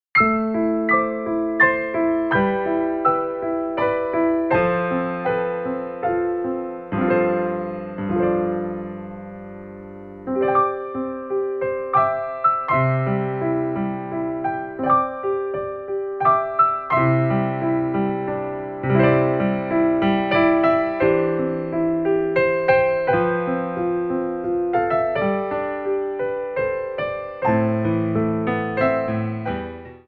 33 Inspirational Ballet Class Tracks
Pliés 2
3/4 (8x8)